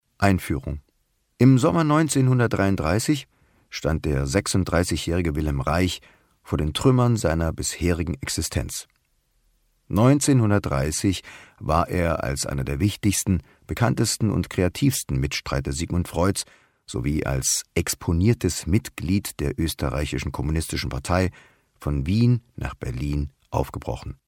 Agentur Sprecherdatei - wir vermitteln Thomas Nicolai Sprecher, Synchronsprecher, Imitator
Sprachproben von Thomas Nicolai